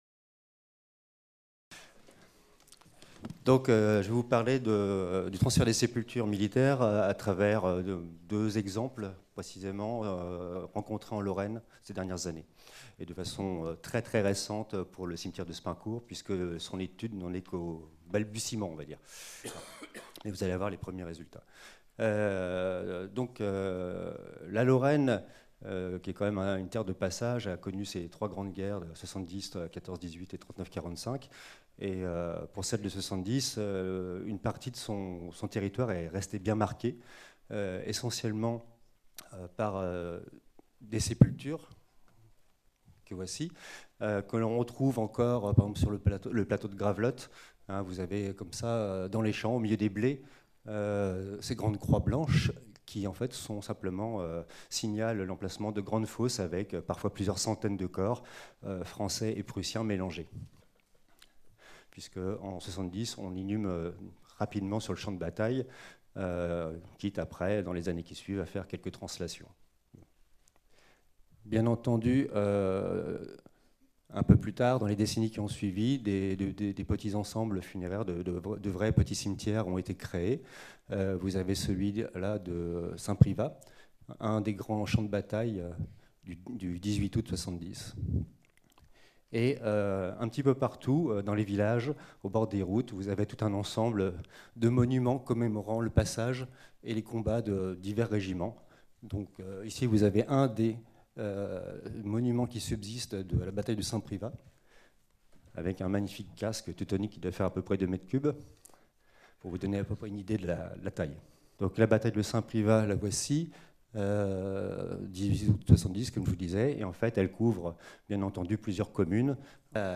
Cette communication a été filmée lors du colloque international intitulé De Verdun à Caen - L’archéologie des conflits contemporains : méthodes, apports, enjeux qui s’est déroulé au Mémorial de Caen les 27 et 28 mars 2019, organisé par la DRAC Normandie, la DRAC Grand-Est, l’Inrap et l’Université de Caen (MRSH-HisTeMé) avec le partenariat de la Région Normandie, du Département du Calvados, de la Ville de Caen et du Groupe de recherches archéologiques du Cotentin.